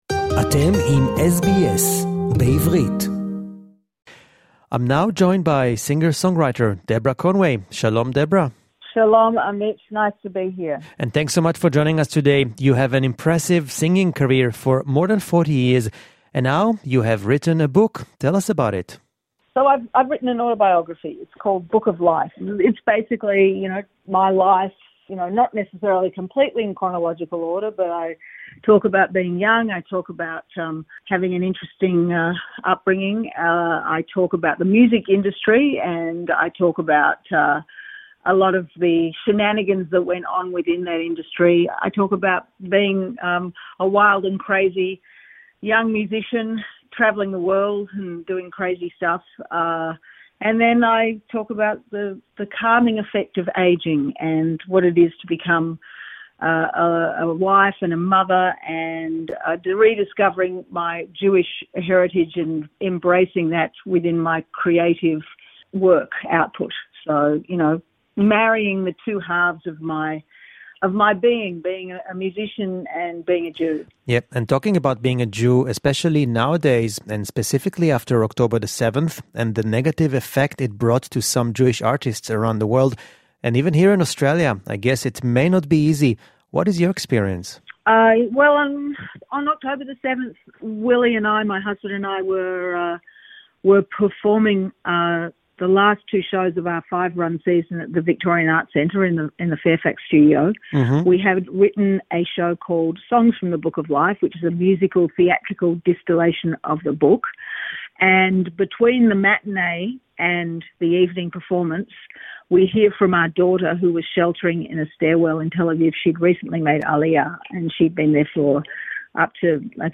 Deborah Conway speaks to SBS Shalom Australia about her new upcoming shows. Songs From The Book of Life is a work of musical theatre written by Deborah Conway & Willy Zygier, based around her memoir 'Book of Life', a shimmering piece of autobiography told in eight scenes, around eight songs with Conway and Zygier exploring the nature of performing, a musical life, the notions of what people know and don’t know about a career lived over four decades in public.